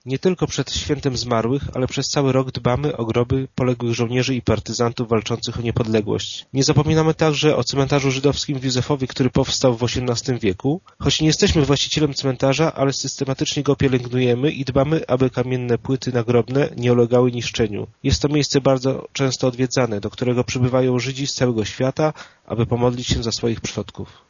Dbamy o żydowską nekropolię oraz miejsca pamięci Polaków, poległych w walce o niepodległość nie tylko przed 1 listopada – zapewnia zastępca burmistrza Ireneusz Wilczyński :